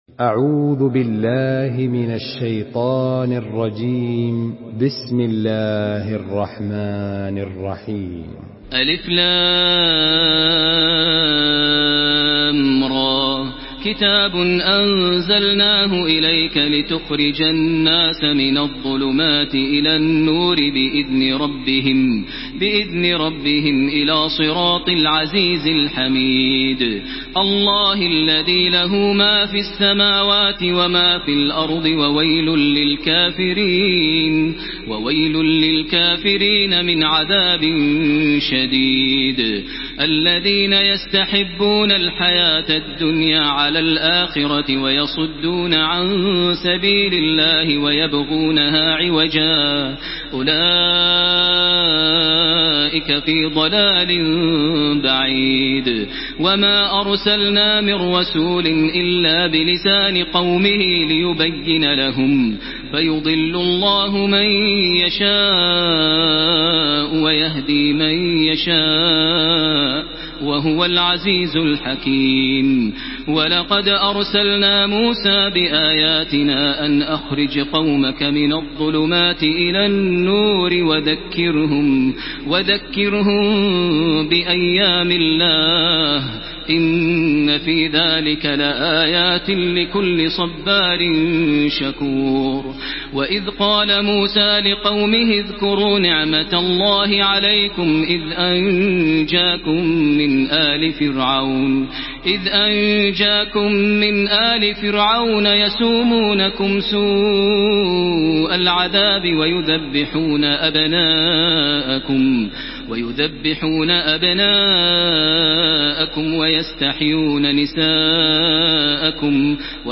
Surah ইব্রাহীম MP3 by Makkah Taraweeh 1433 in Hafs An Asim narration.